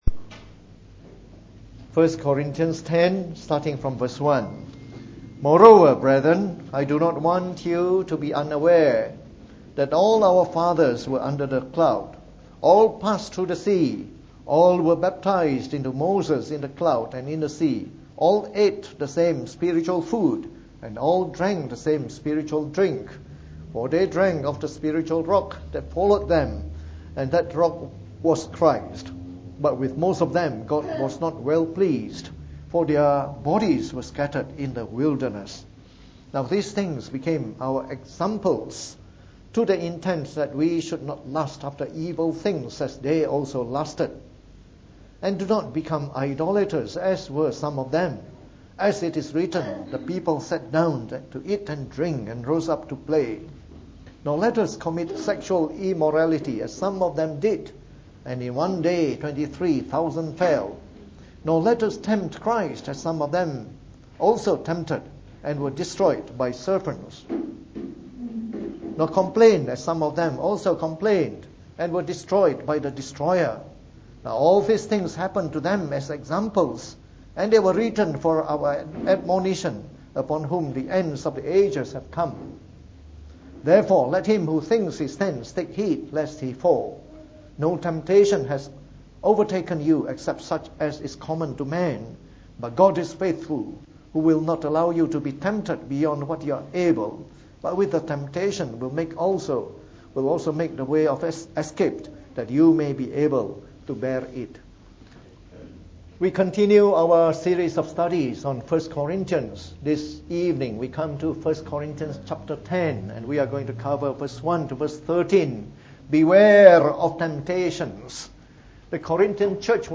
From our series on 1 Corinthians delivered in the Evening Service.